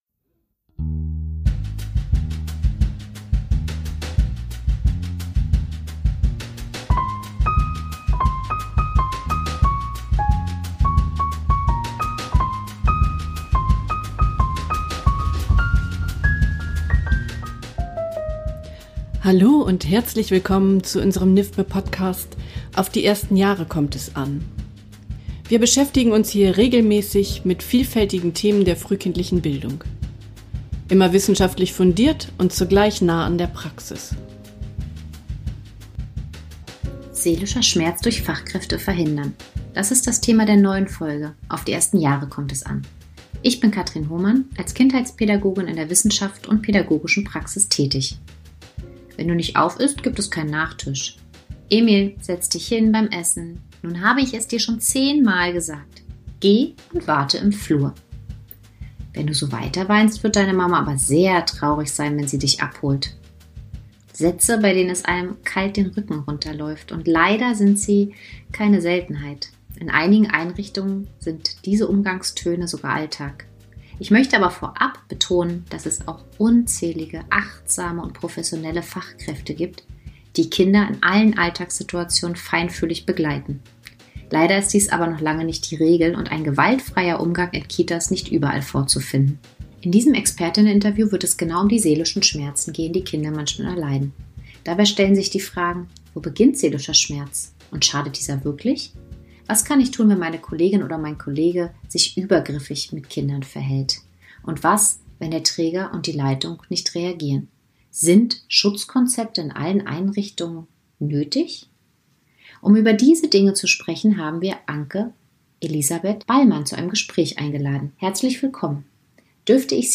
In diesem Experteninterview wird es genau um diese seelischen Schmerzen gehen, die Kinder erleiden.